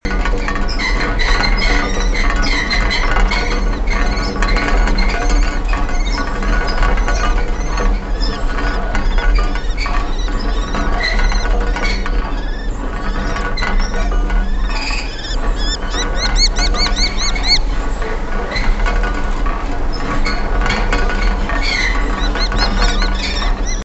Australian Nature Sounds
Rusty Windmill Rusty Windmill
Windmill.mp3